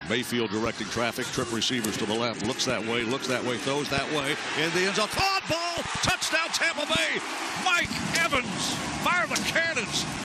PBP-Buccaneers-7-0-Evans-2-Yd-TD-Rec-3.mp3